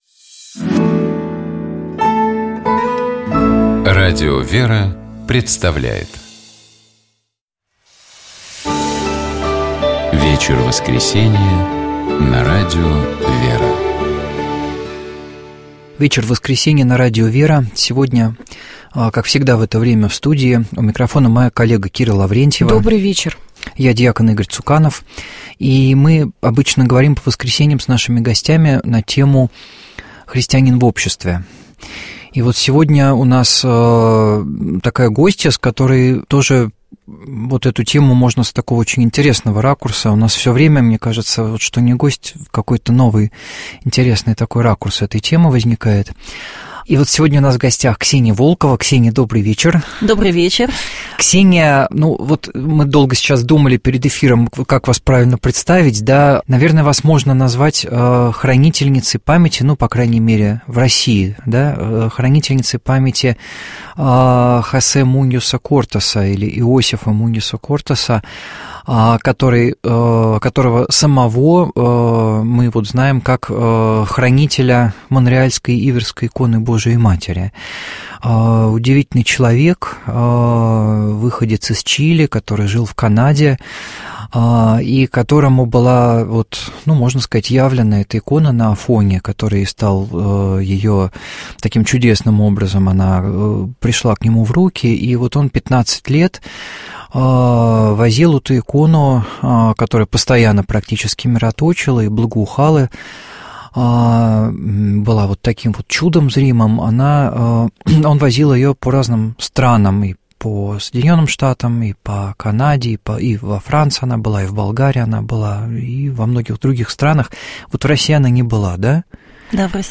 У нас в гостях была журналист